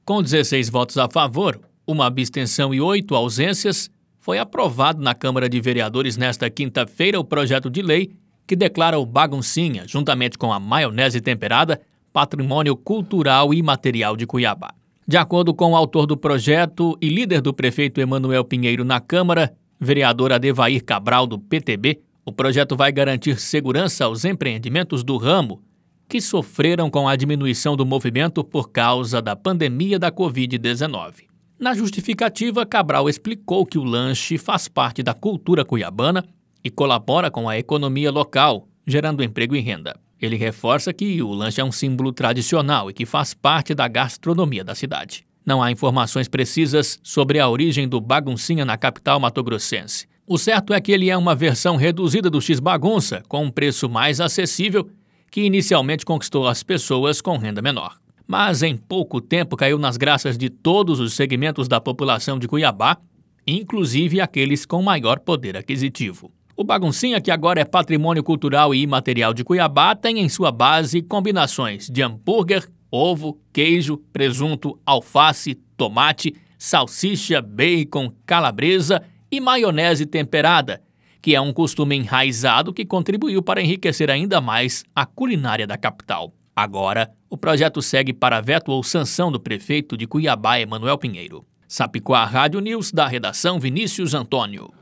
Boletins de MT 04 mar, 2022